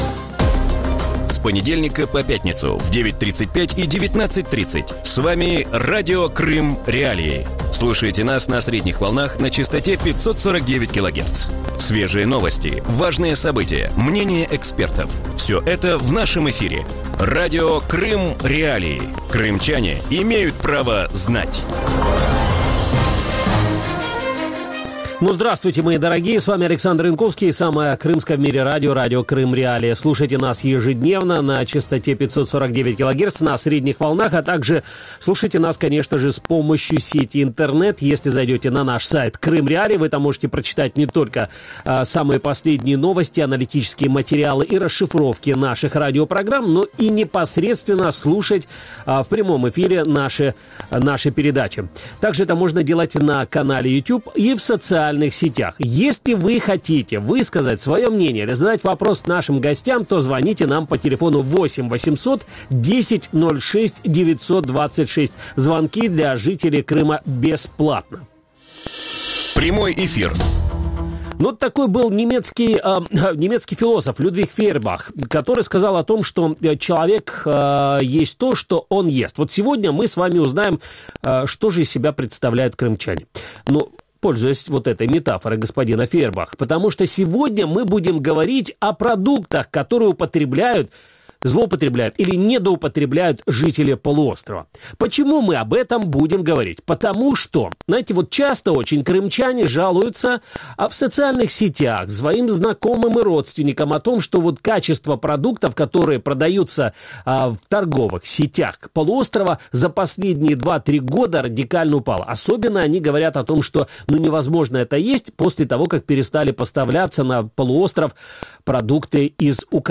У вечірньому ефірі Радіо Крим.Реалії говорять про якість продуктів харчування на полицях кримських магазинів. Як змінилася їх якість після анексії півострова, що додають в продукти російські підприємства для здешевлення продукції і як неякісні продукти впливають на здоров'я?